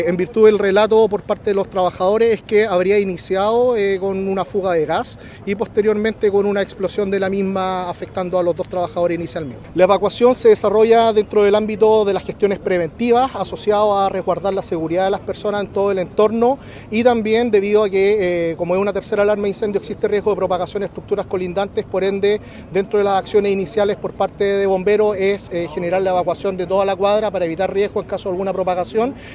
El director regional de Senapred, Mitzio Riquelme, entregó información preliminar del origen del incendio.